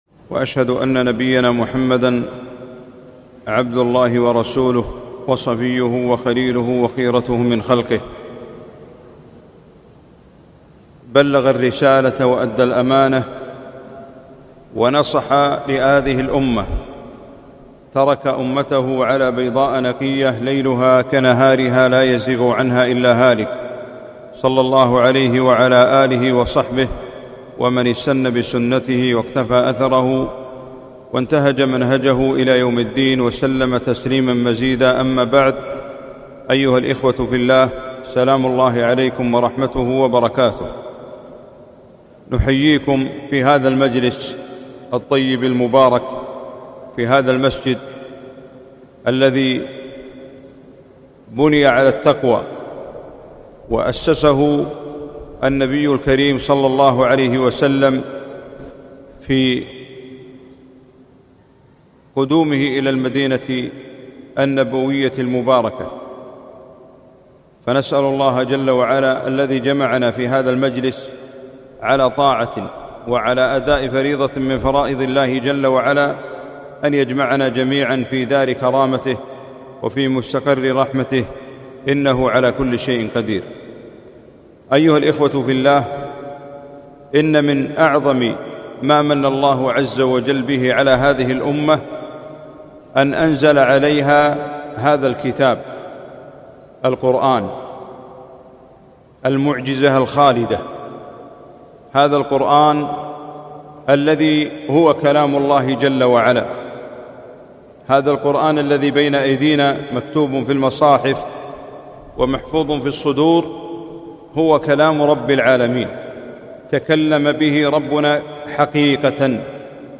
كلمة - القرآن في شهر رمضان.